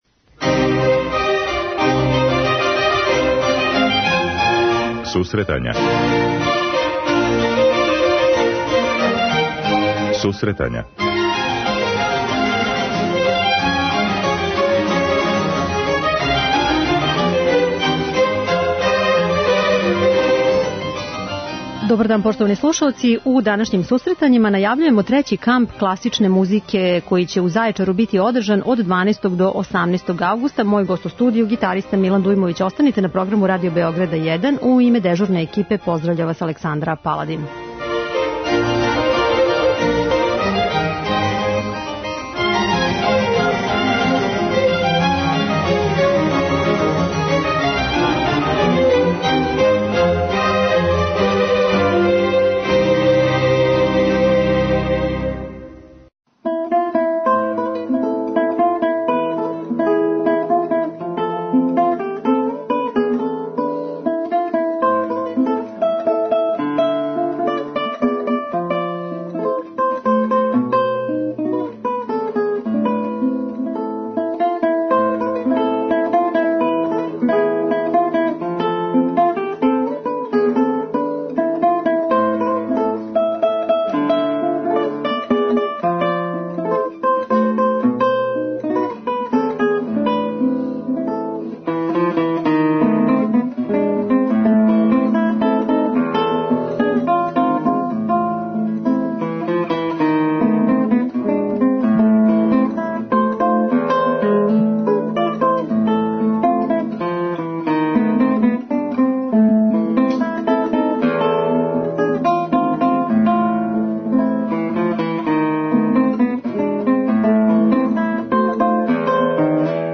Током емисије слушаћемо ауторске композиције за гитару овог уметника.